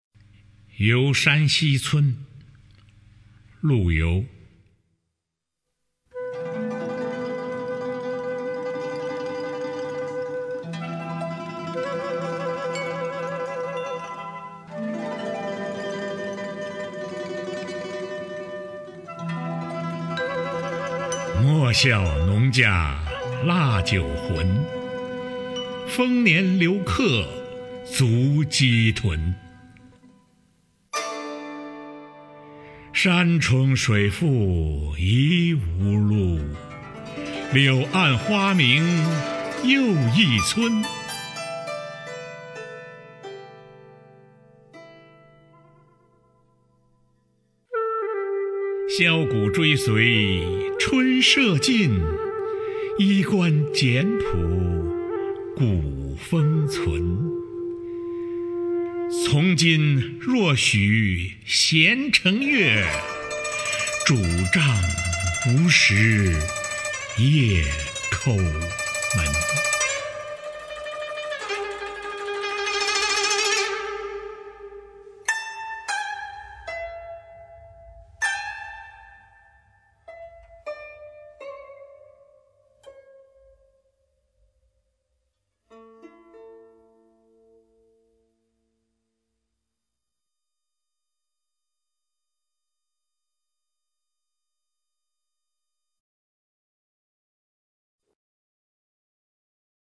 [宋代诗词朗诵]陆游-游山西村（男） 古诗词诵读